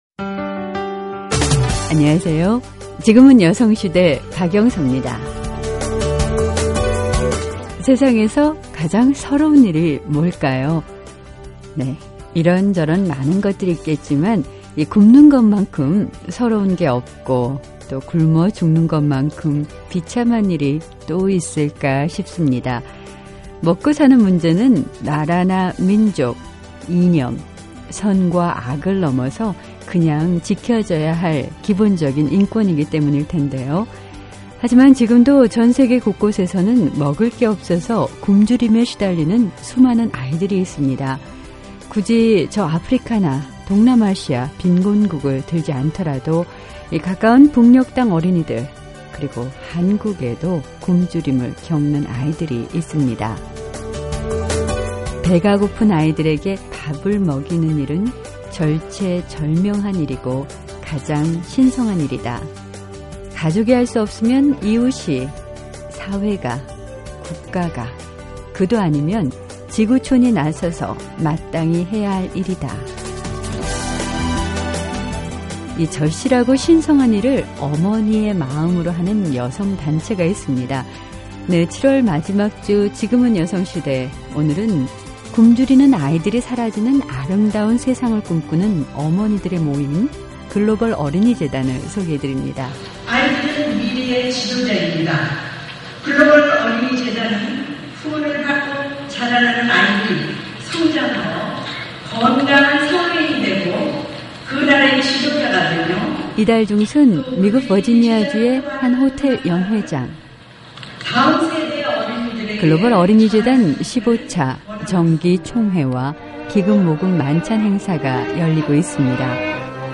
배고픈 아이들에게 밥을 먹이는 일, 절실하고 신성한 이 일을 어머니의 마음으로 하는 여성 단체가 있습니다. 지금은 여성시대, 오늘은 글로벌 어린이 재단 창립 기념 행사장을 찾아가봤습니다.